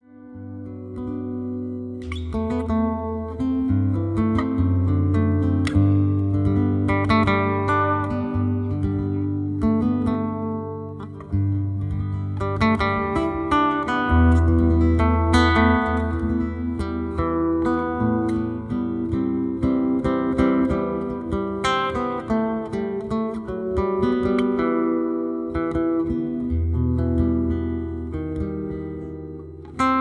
Soothing and Relaxing Guitar Music